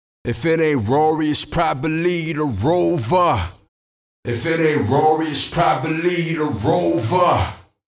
Если с уже записанным вокалом работаете, попробуйте даблеры (в примере - MUnison) + рум (короткий ревер).